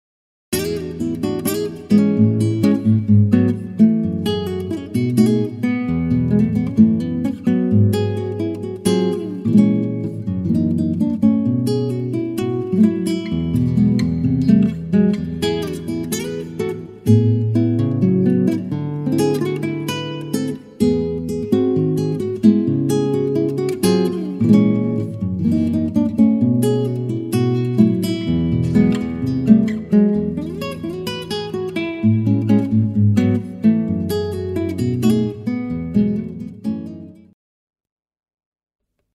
Fingerstyle guiitar
all original pieces
is played exclusively on a nylon string guitar